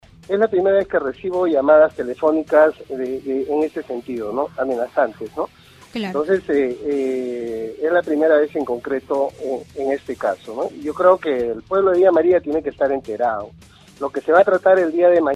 En una entrevista exclusiva para Radio Stereo Villa indicó que no se dejó amedrentar y que ya realizó la denuncia correspondiente, a fin de salvaguardar la integridad de su familia así como la suya.